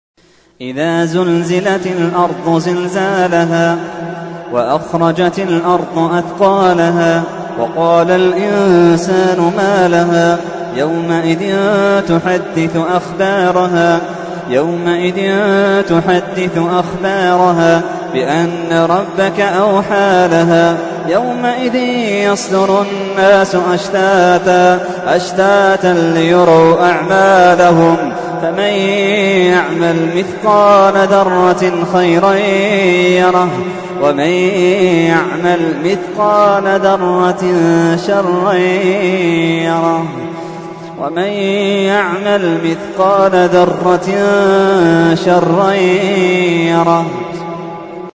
تحميل : 99. سورة الزلزلة / القارئ محمد اللحيدان / القرآن الكريم / موقع يا حسين